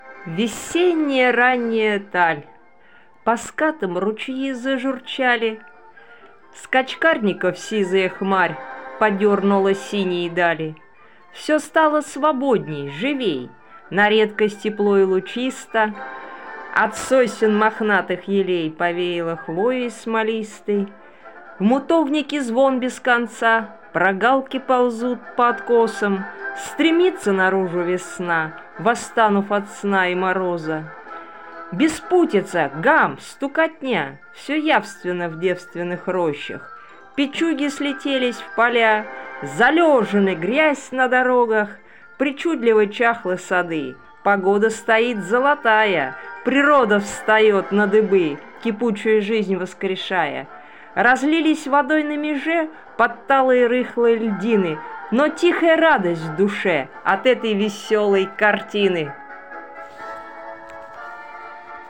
Музыка классики